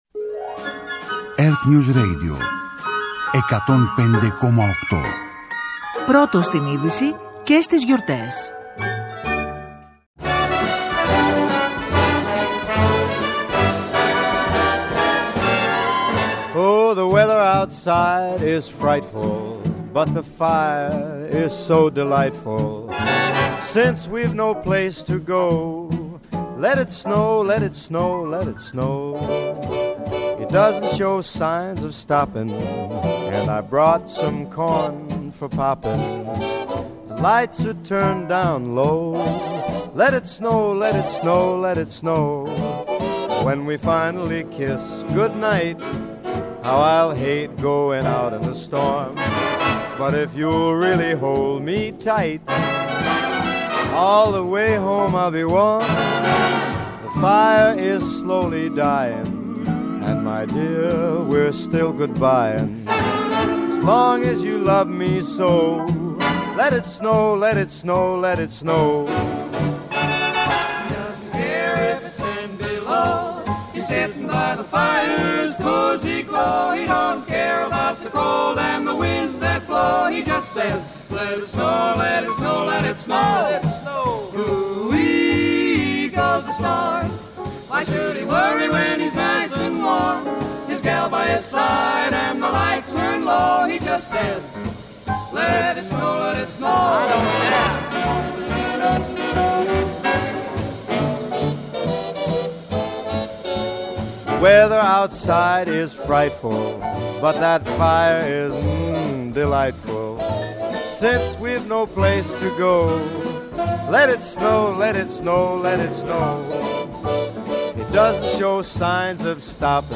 Backstage αποκαλύψεις, ιστορίες και αναμνήσεις από τις ταινίες και τα τραγούδια που εδώ και δεκαετίες σημαδεύουν τα Χριστούγεννά μας. Από τα Κάλαντα και τον Grinch, μέχρι το Μόνος στο Σπίτι και το μαγικό ταξίδι του The Polar Express, το χριστουγεννιάτικο σελιλόιντ ξετυλίγεται… ζωντανά στο ραδιόφωνο.